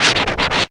FAT SCRATCH.wav